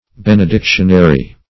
Search Result for " benedictionary" : The Collaborative International Dictionary of English v.0.48: Benedictionary \Ben`e*dic"tion*a*ry\, n. A collected series of benedictions.